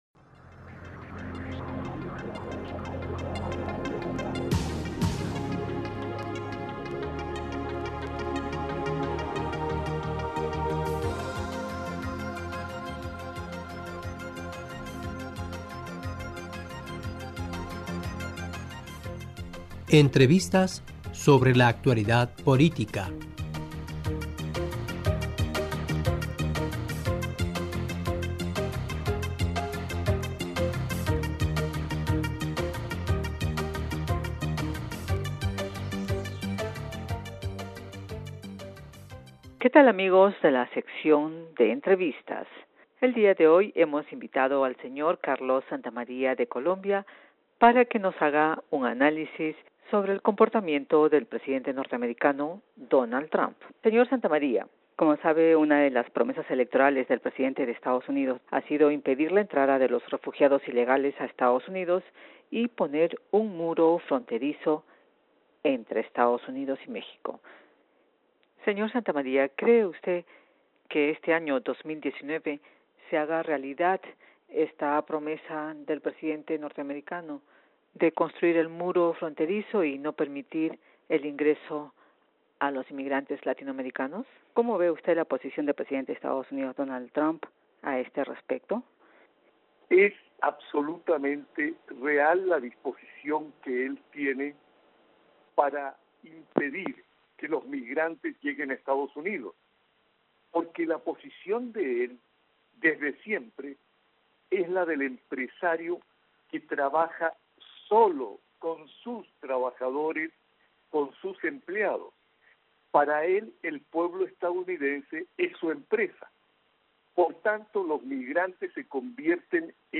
Que tal amigos de la sección de entrevistas.